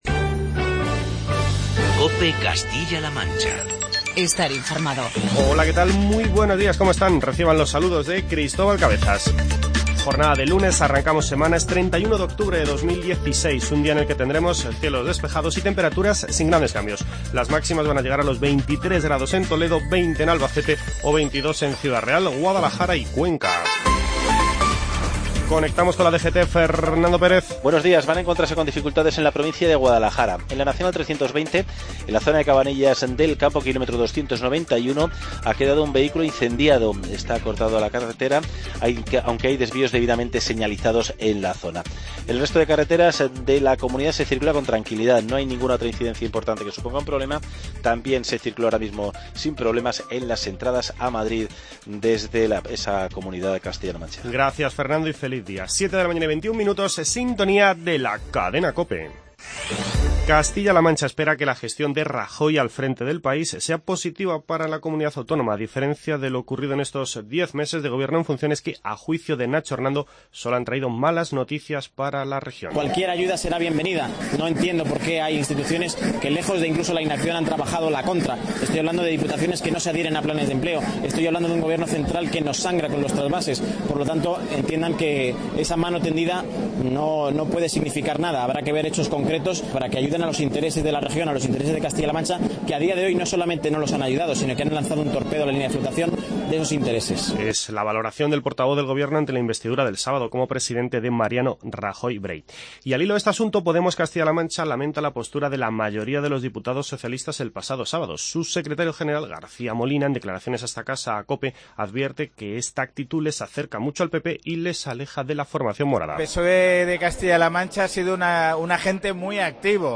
Informativo COPE Castilla-La Mancha
AUDIO: Comenzamos con las palabras de Nacho Hernando, portavoz del Gobierno de Castilla-La Mancha.